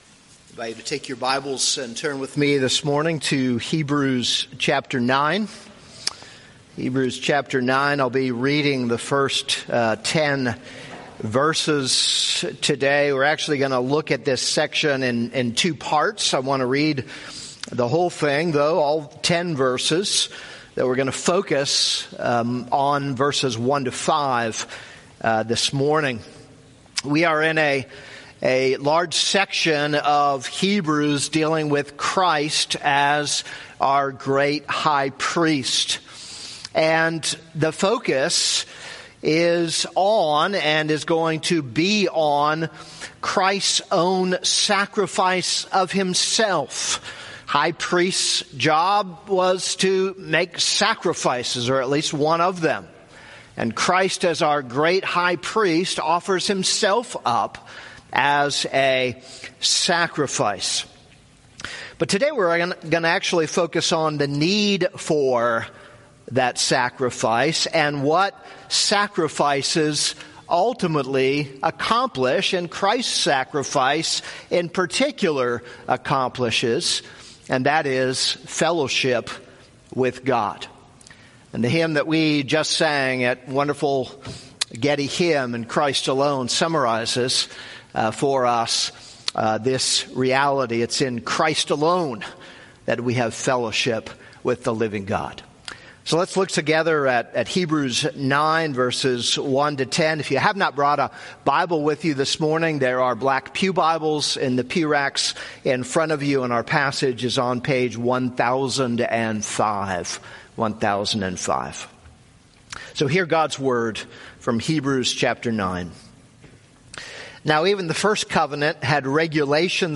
This is a sermon on Hebrews 9:1-10.